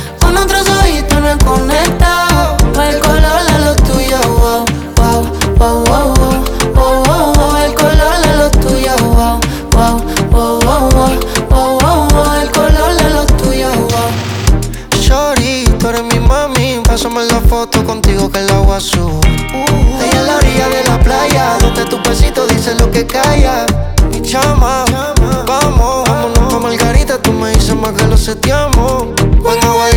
Модульные синтезаторы и глитчи трека
Electronica Electronic Latin
Жанр: Электроника